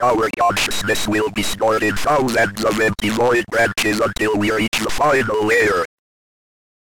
SAM: Software Automatic Mouth